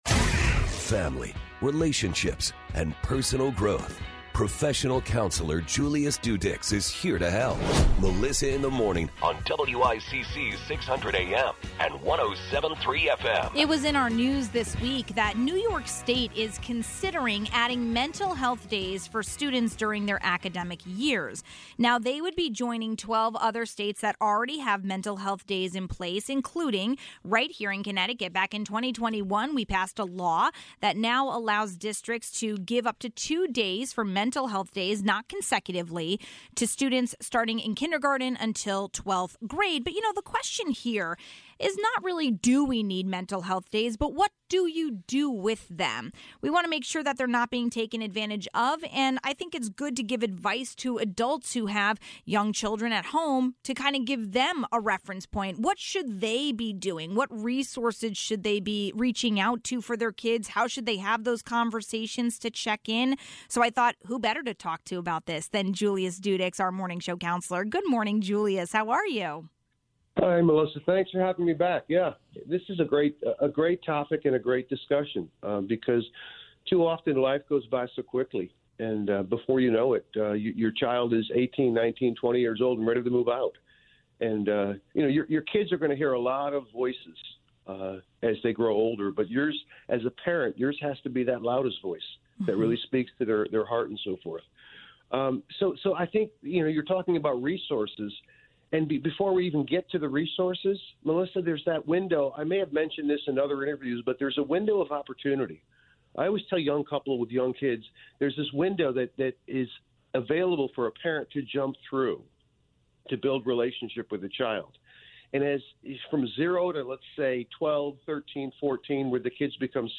We got insight from professional counselor